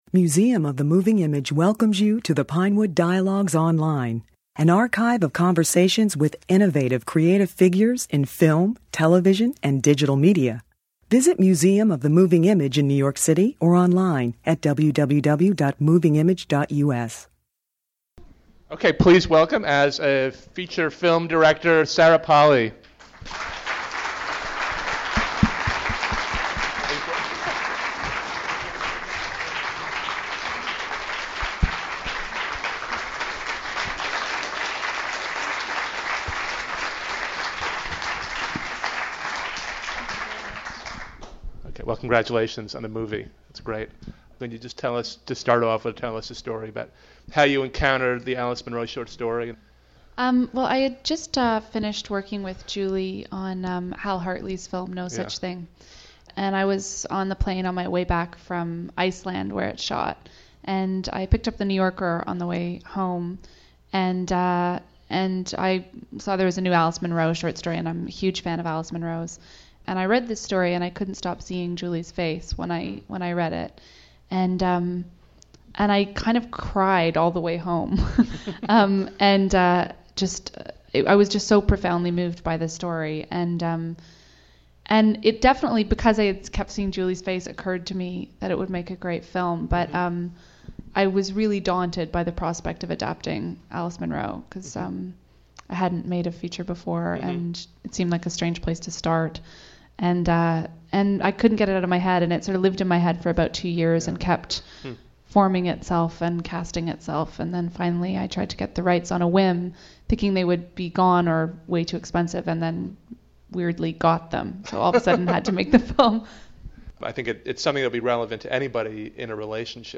In this conversation, Polley discusses how she interpreted Munro's tale about an aging couple's struggle with Alzheimer's Disease as a universal love story.